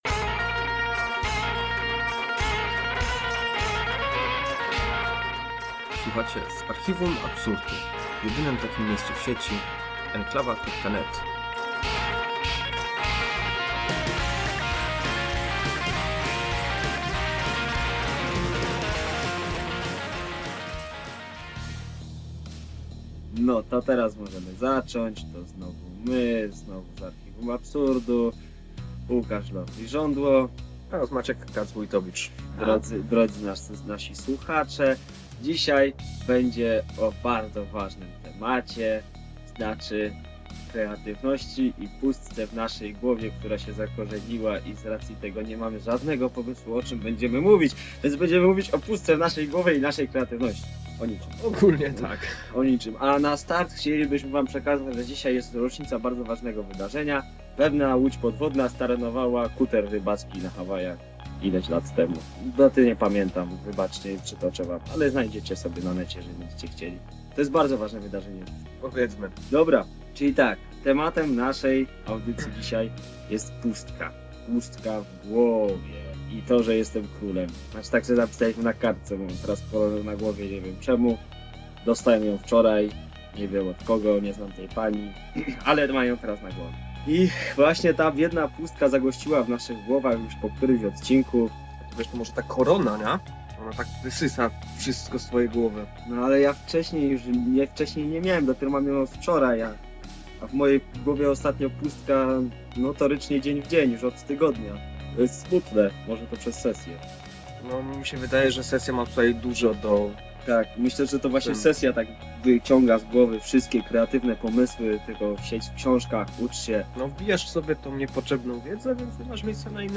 O pustce w głowie, czarnych dziurach, oraz o próbach założenia własnego państwa. Z góry przepraszamy za słabą jakość spowodowaną nagrywaniem w warunkach "polowych".